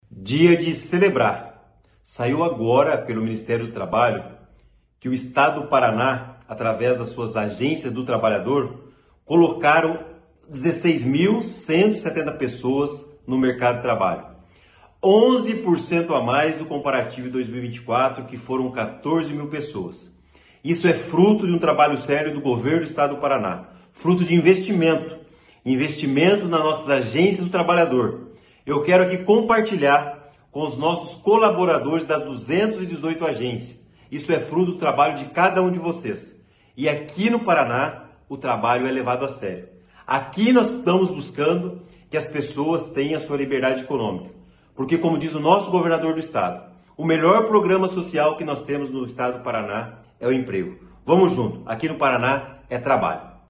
Sonora do secretário Estadual do Trabalho, Qualificação e Renda, Do Carmo, sobre o 2º melhor resultado do ano da rede Sine na condução de pessoas a empregos no Paraná